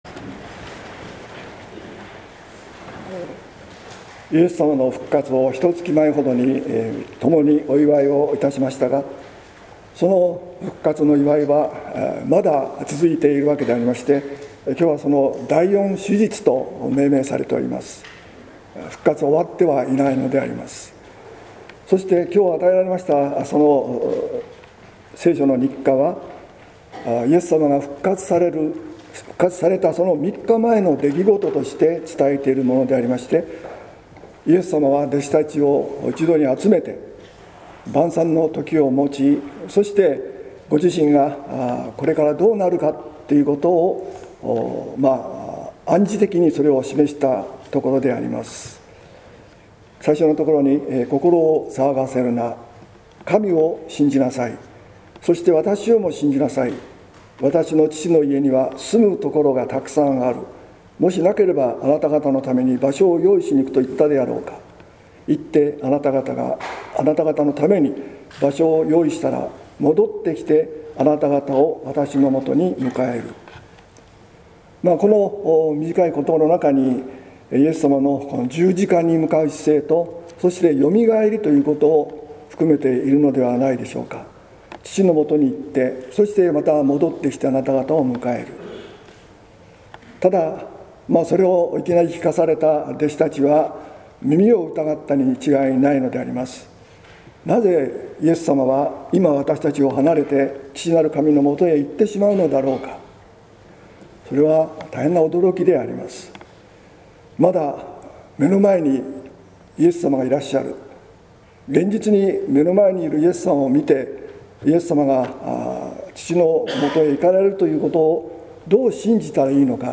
説教「主よ、何処へ」（音声版）